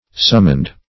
Summoned - definition of Summoned - synonyms, pronunciation, spelling from Free Dictionary